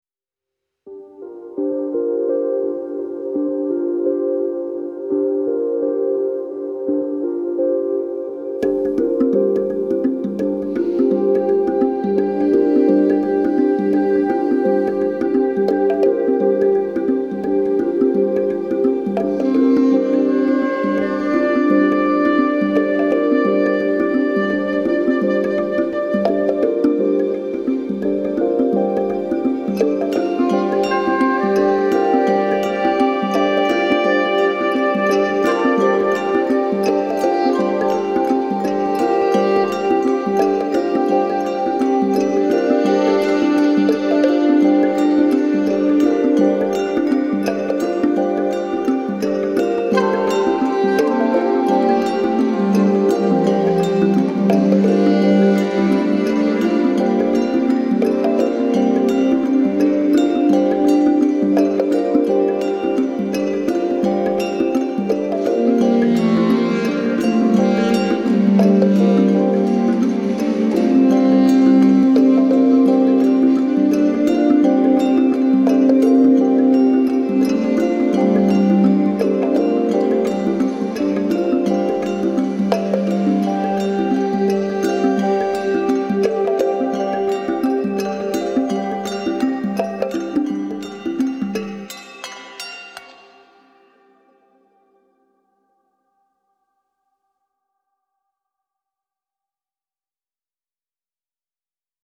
Dark dream pop music with broad soundscapes.